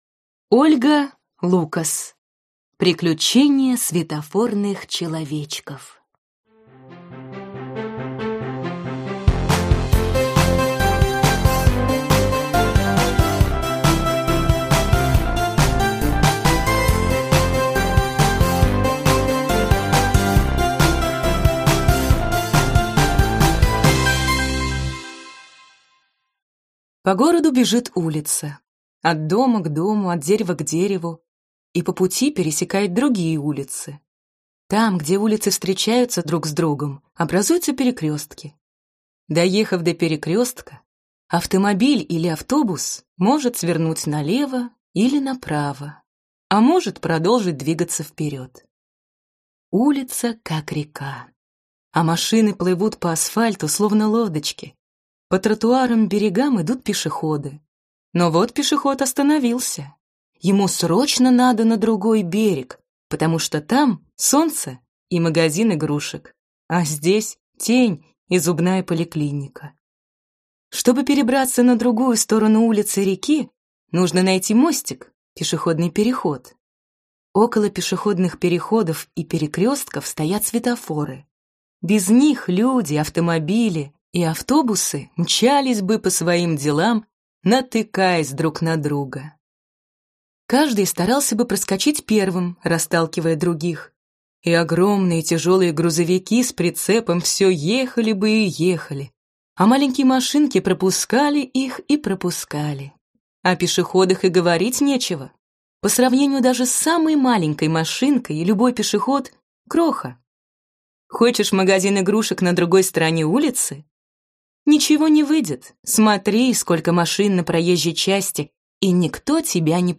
Аудиокнига Приключения светофорных человечков | Библиотека аудиокниг